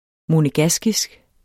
monegaskisk adjektiv Bøjning -, -e Udtale [ monəˈgasgisg ] Betydninger 1. fra Monaco; vedr.